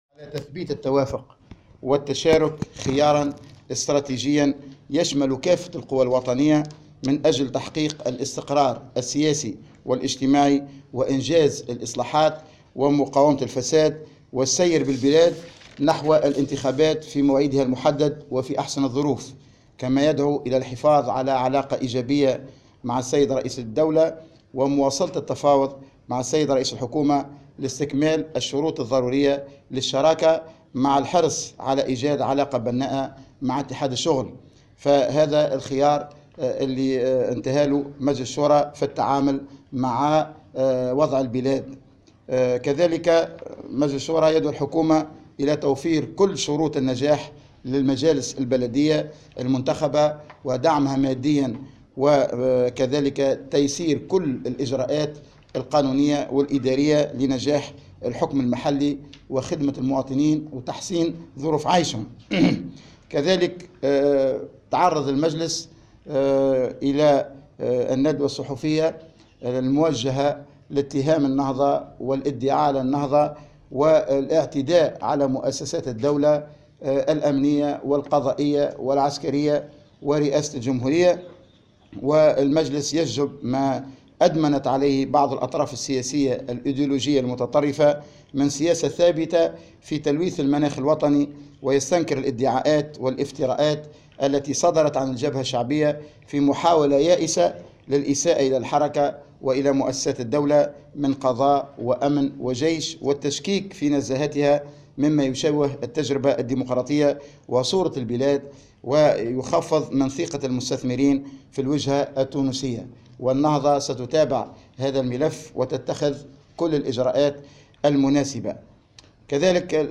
وقال في ندوة صحفية واكبتها مراسلة "الجوهرة اف أم" إن مجلس شورى حركة النهضة دعا إلى إلى الحفاظ على علاقة ايجابية مع رئيس الدولة وإلى مواصلة التفاوض مع رئيس الحكومة لاستكمال الشروط الضرورية للشراكة مع الحرص على ايجاد علاقة بناءة مع اتحاد العام التونسي الشغل.